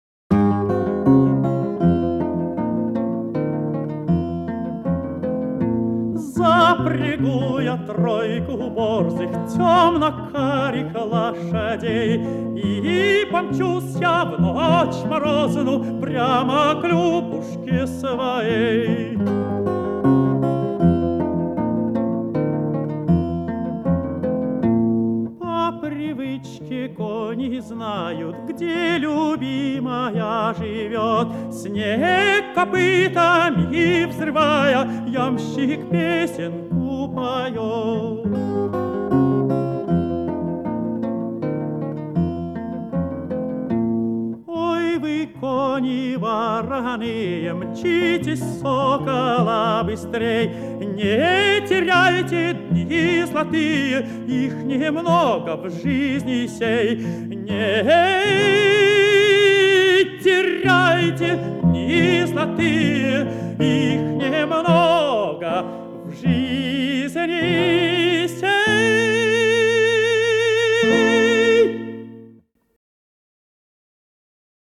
русская песня